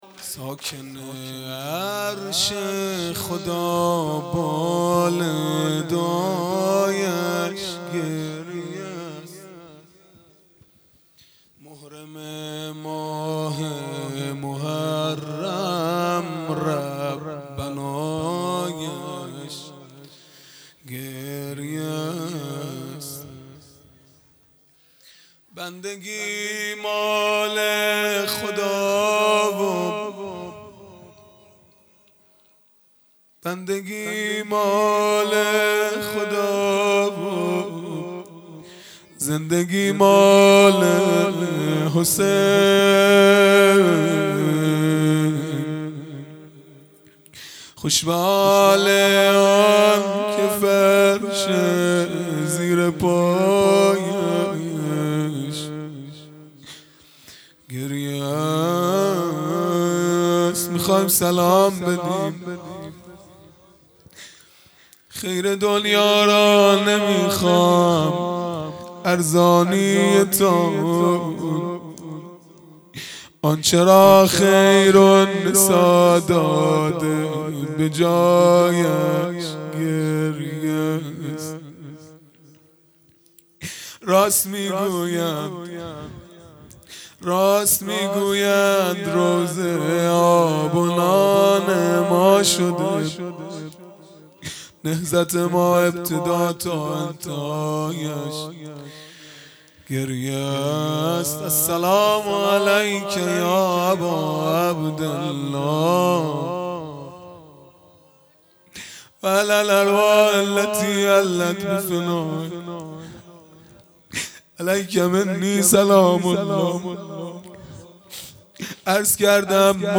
مناجات پایانی | ساکن عرش خدا بال دعایش گریه است | دوشنبه ۲۵ مرداد ۱۴۰۰
دهه اول محرم الحرام ۱۴۴۳ | شب هشتم | دوشنبه ۲۵ مرداد ۱۴۰۰